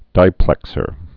(dīplĕk-sər)